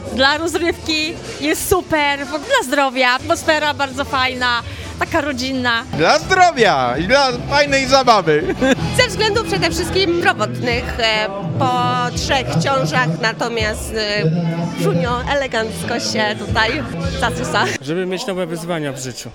Stargardzkie Morsy spotkały w sobotę się nad jeziorem Miedwie, by wspólnie wejść do zimnej wody i rozpocząć nowy rok morsowania.
W morsowaniu wzięło udział wielu uczestników – zapytaliśmy ich, czemu morsują.